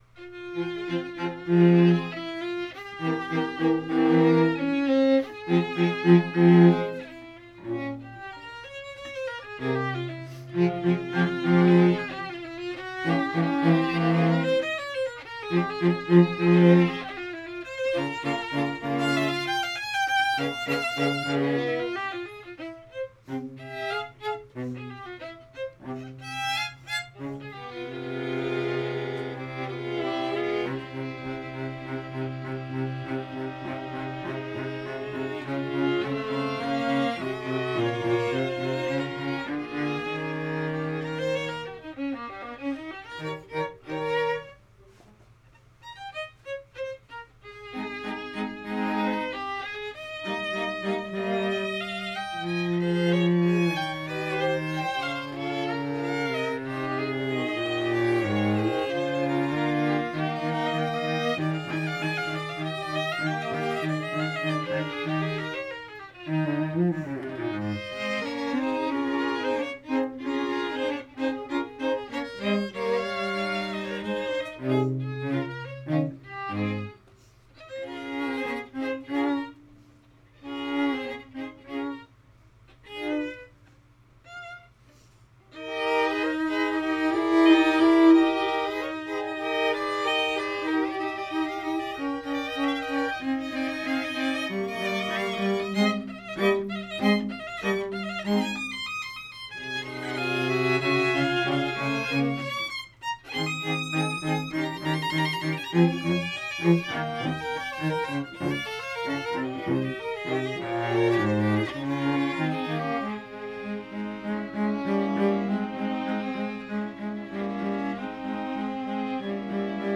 2:00 PM on July 20, 2014, "Music with a View"
Chamber Groups
Allegro moderato